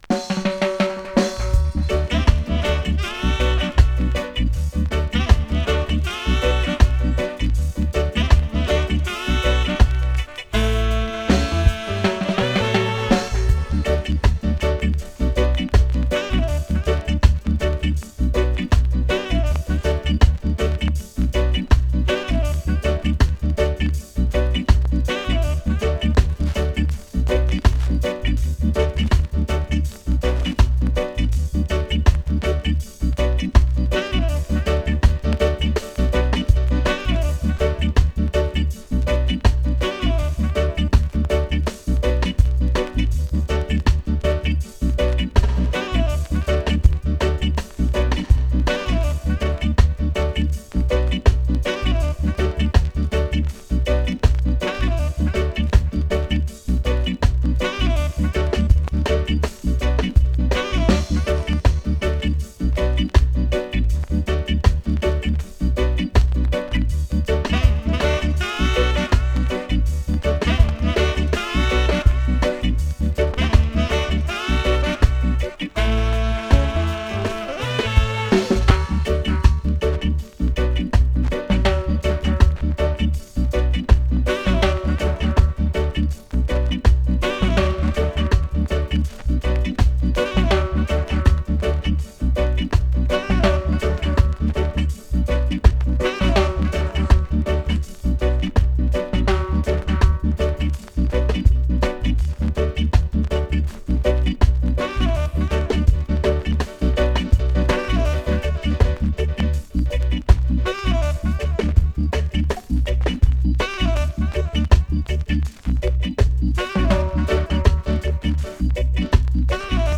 son roots du reggae
Si on compare l'instrumental du disque original